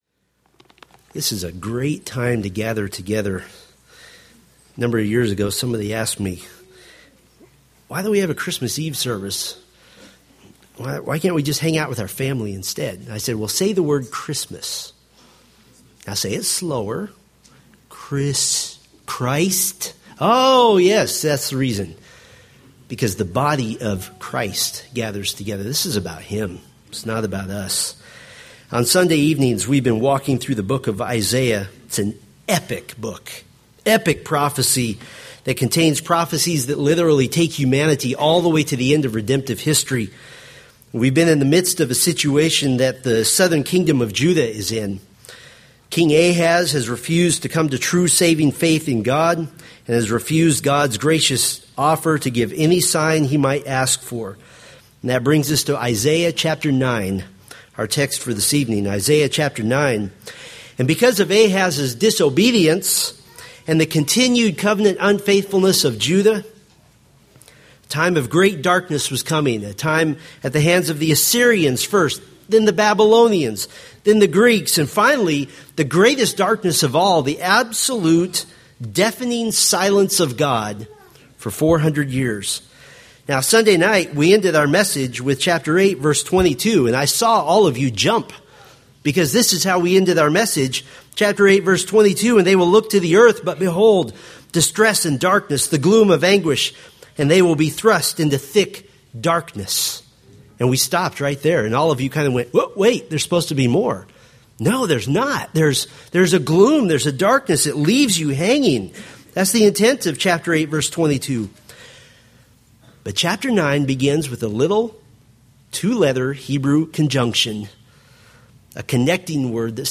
Homepage of Steadfast in the Faith, anchoring the soul in the Word of God by providing verse-by-verse exposition of the Bible for practical daily living.